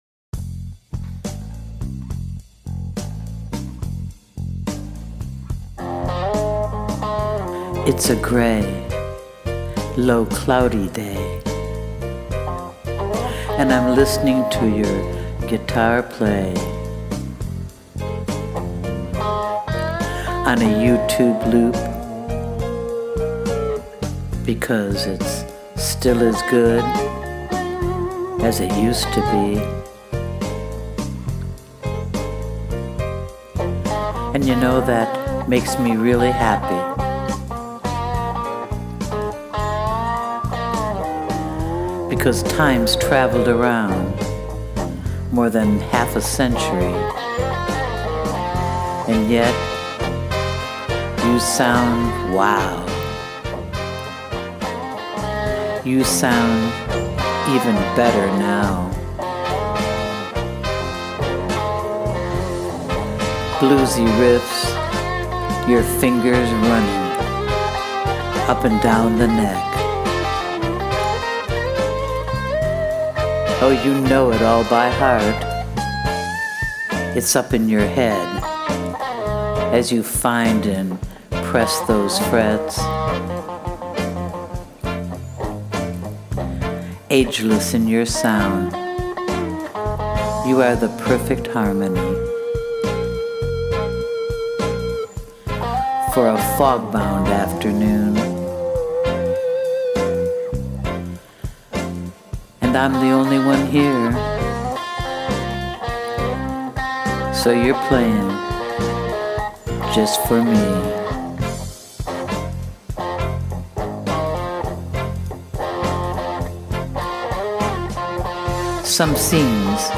Your voice and poem over the wailin’ guitar are just perfect!